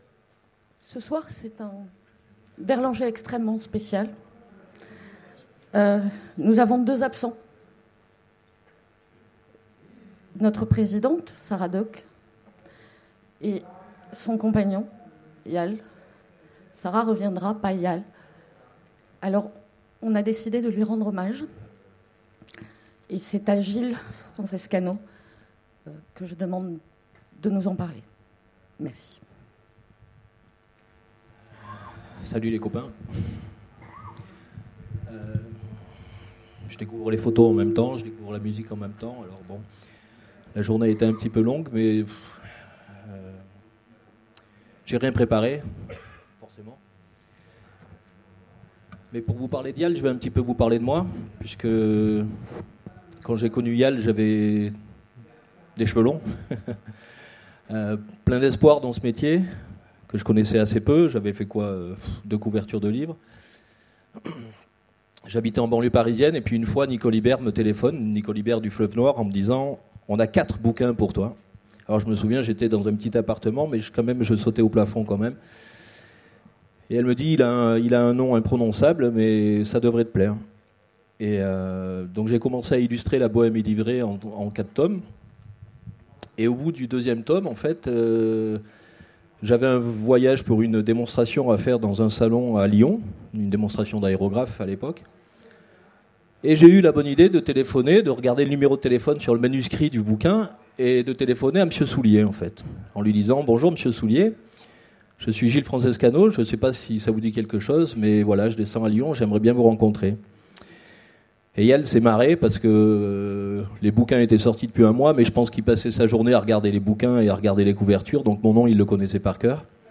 Remise de prix Conférence